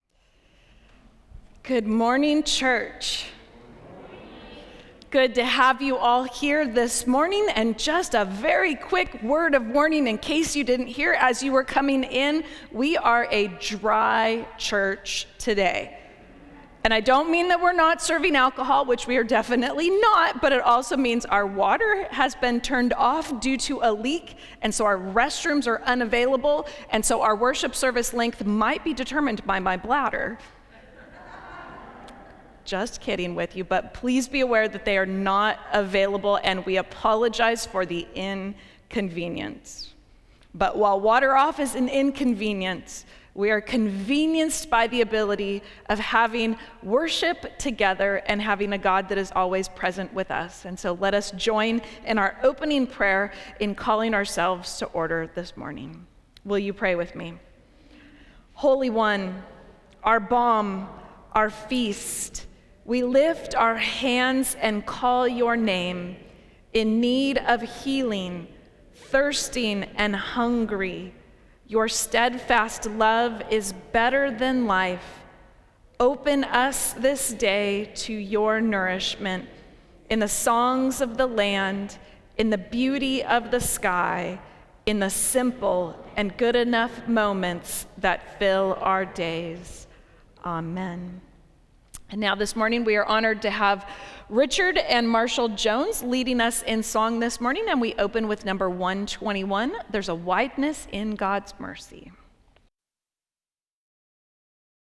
Service of Worship
Welcome and Opening Prayer
open_prayer.mp3